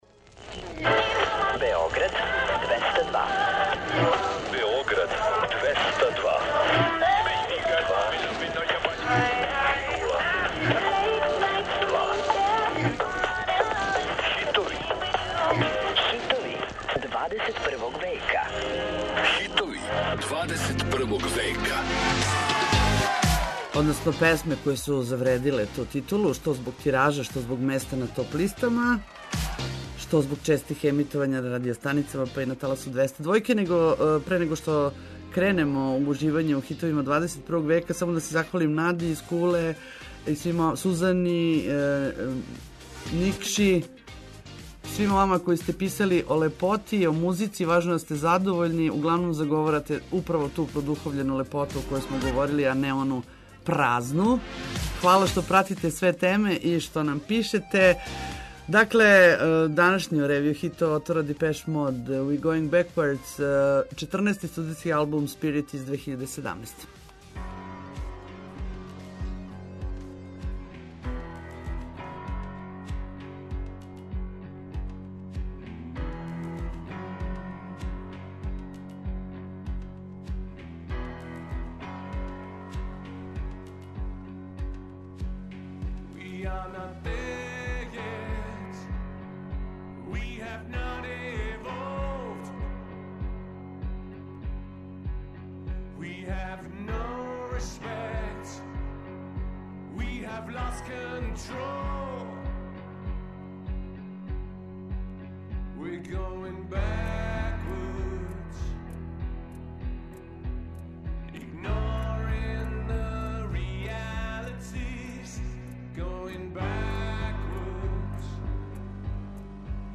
Сваког радног дана, у термину 13-14 сати, подсећамо вас на хитове новог миленијума. Песме које можете да слушате су, од 2000. године до данас, биле хитови недеље Београда 202, или су се налазиле на првим местима подлиста новитета Топ листе 202.